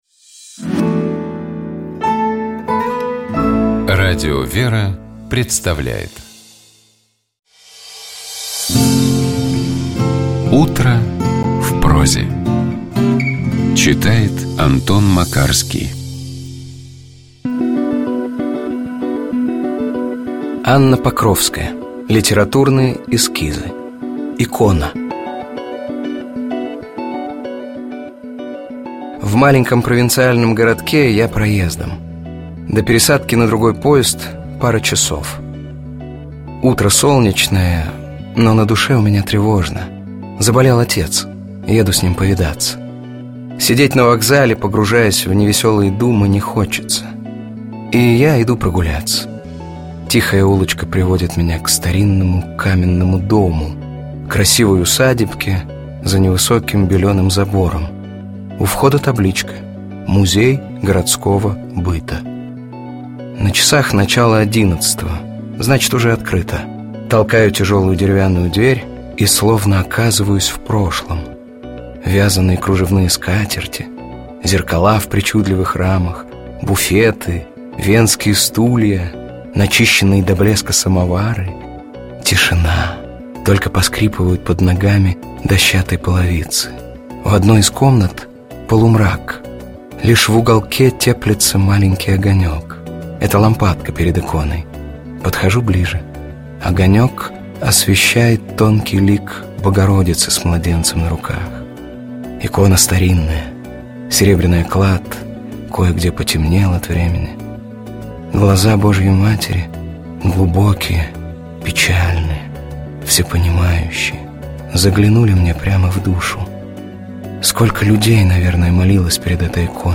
Текст Анны Покровской читает Антон Макарский.